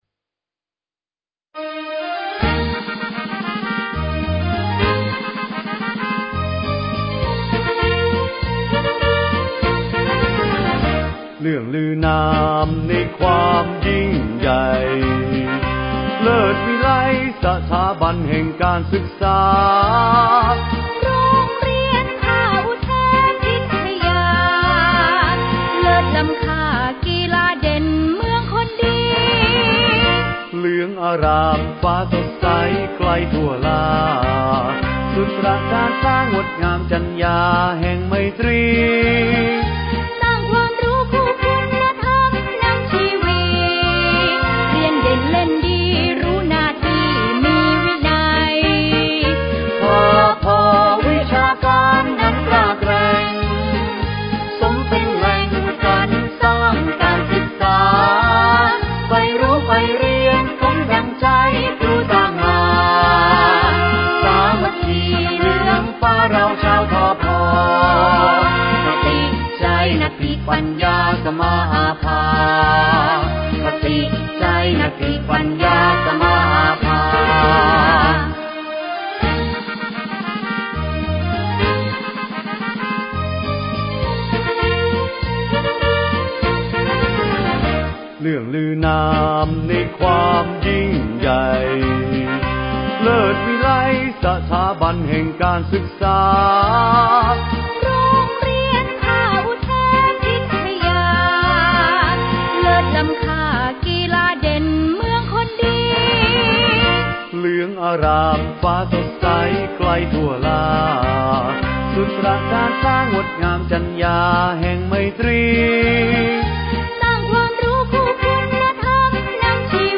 เพลงโรงเรียน